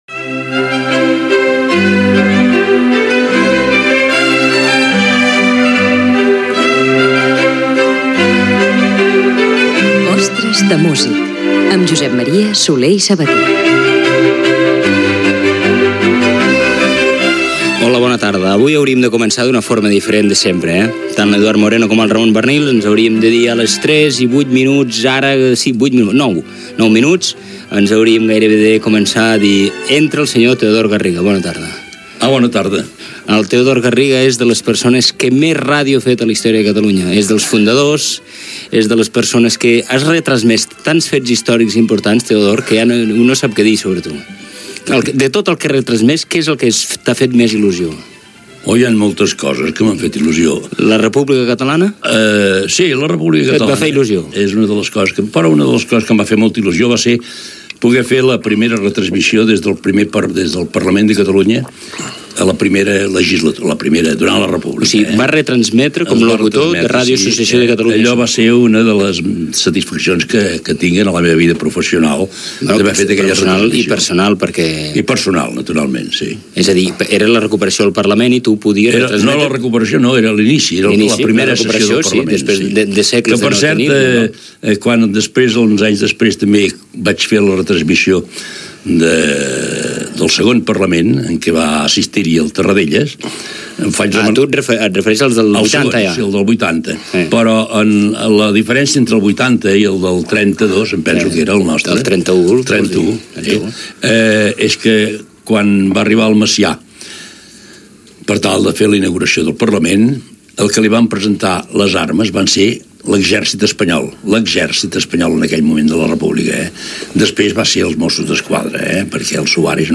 Careta del programa, tertúlia